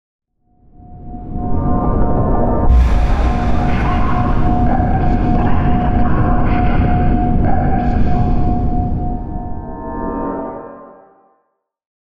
divination-magic-sign-circle-complete.ogg